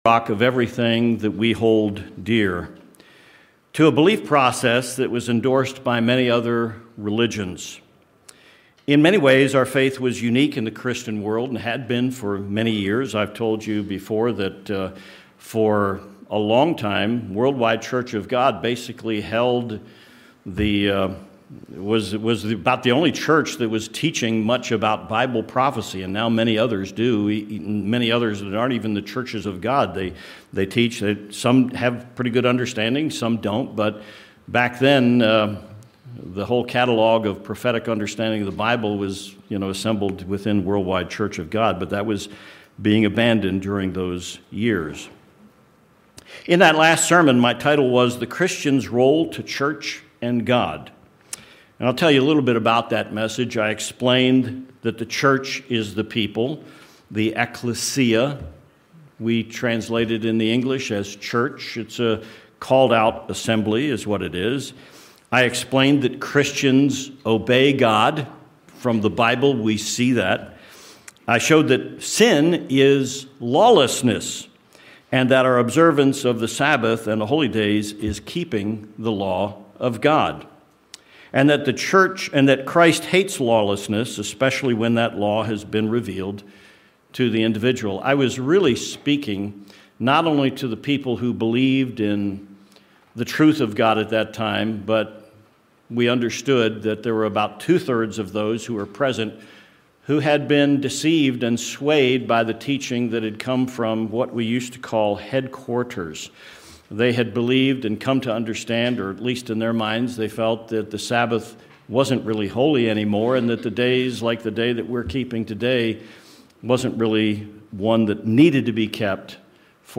First Day of Unleavened Bread Given on Apr 13
Given in Tucson, AZ El Paso, TX